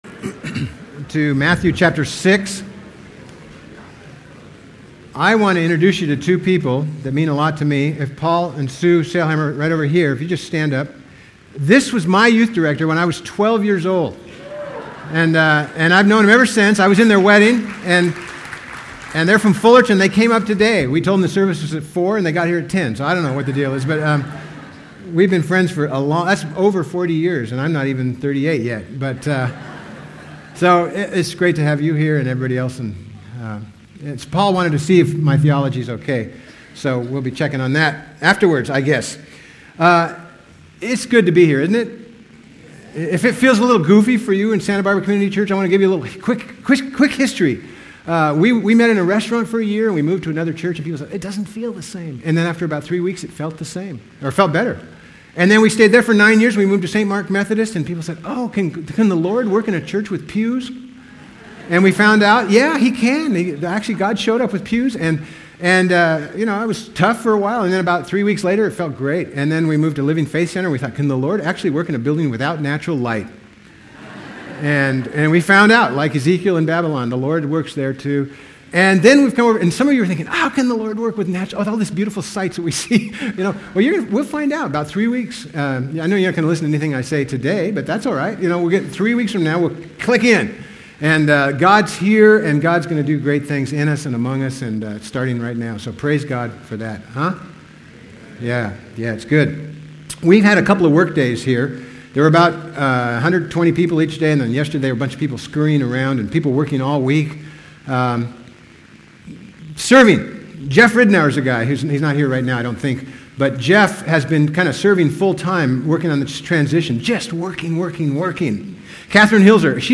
Sermon on the Mount…